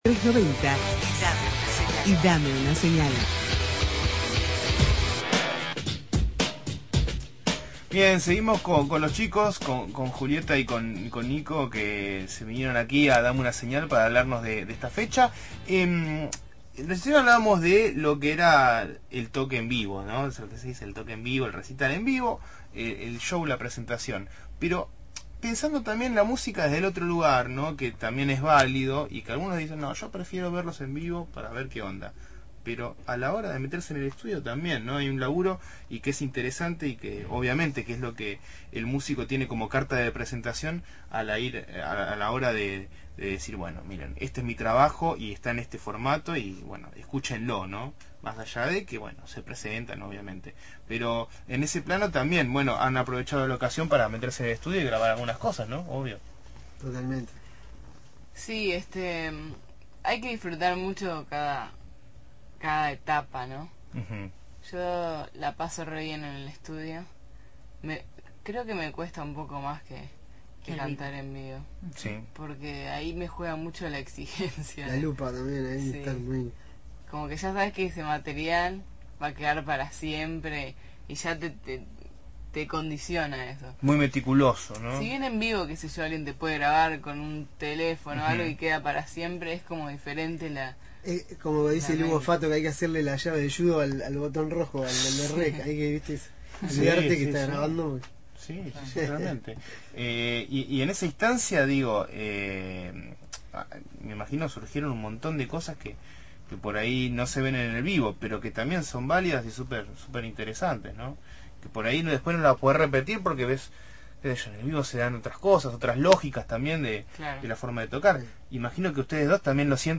Entrevista a la cantante Julieta Rada sobre su próxima presentación en La Plata.